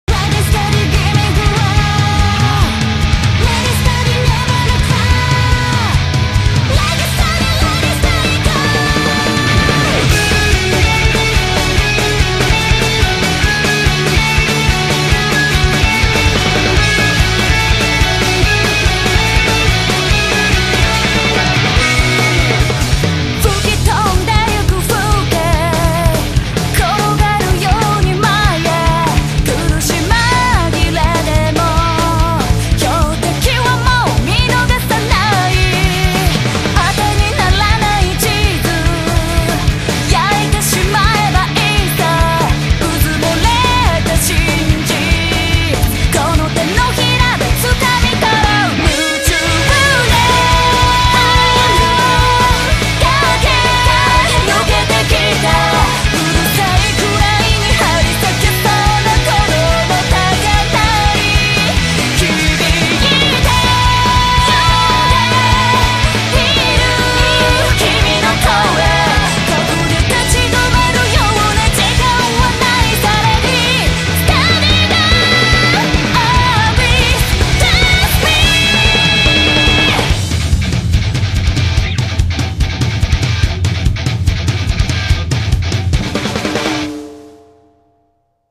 BPM144
Audio QualityCut From Video